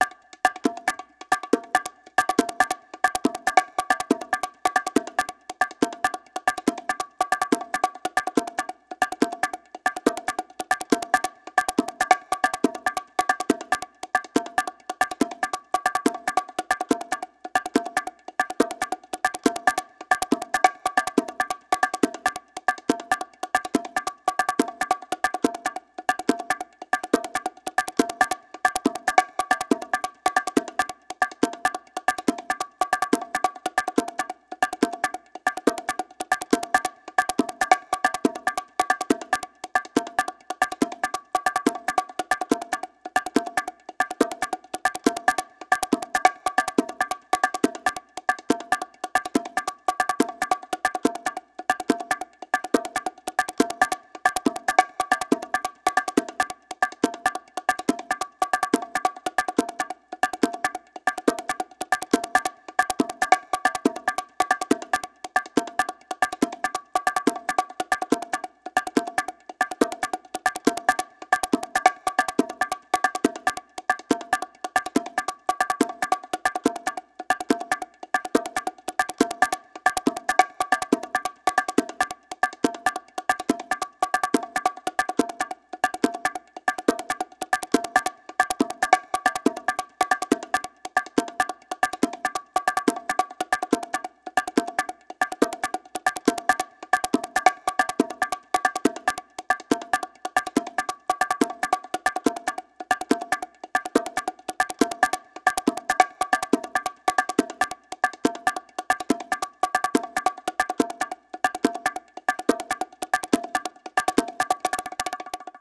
Clock5
clock5.wav